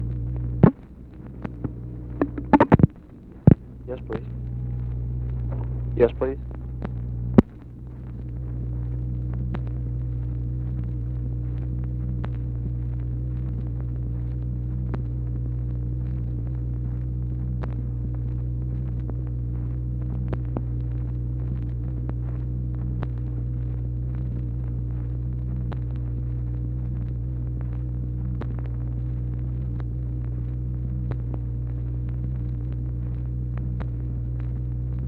"YES, PLEASE" ONLY SPOKEN WORDS
Conversation with SIGNAL CORPS OPERATOR, January 1, 1964
Secret White House Tapes